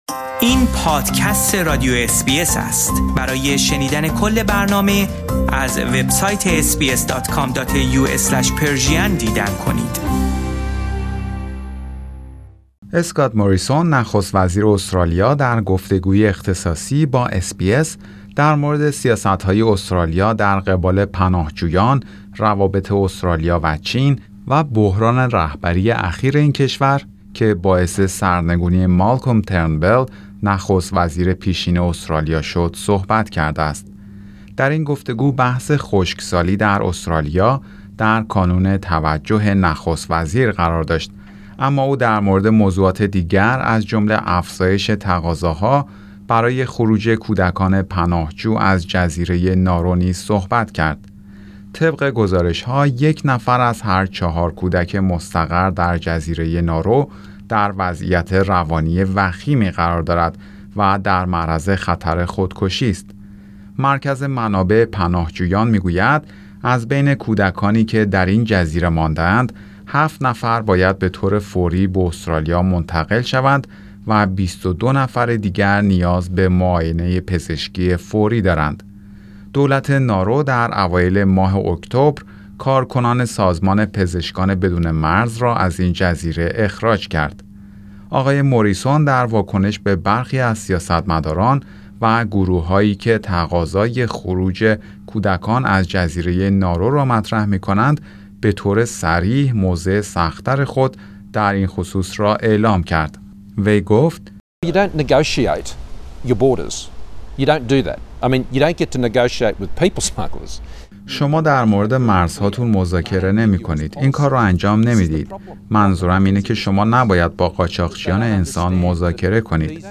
اسکات موریسون نخست وزیر استرالیا در گفتگویی اختصاصی با SBS، مورد سیاست های استرالیا در قبال پناهجویان، روابط استرالیا و چین و بحران رهبری اخیر استرالیا که باعث سرنگونی مالکوم ترنبل نخست وزیر سابق این کشور شد صحبت کرد.